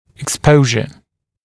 [ɪk’spəuʒə] [ek-][ик’споужэ] [эк-]обнажение, экпозиция(величина видимости зубов в соответвующем положении, обычно в состоянии покоя или при улыбке); подверженность воздействию